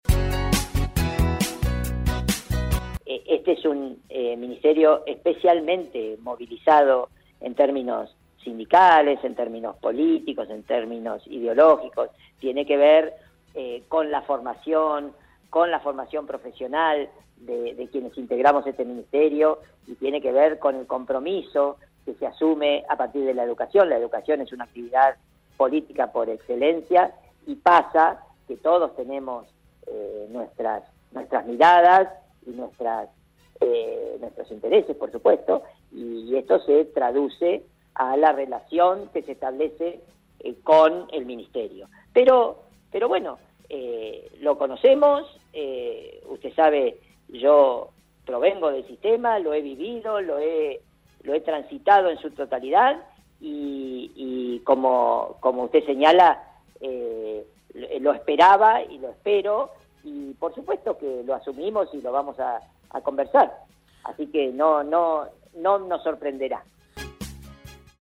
El ministro de educación de la provincia, José Luis Punta, analiza políticamente su cartera y así lo expresó al aire de RADIOVISIÓN: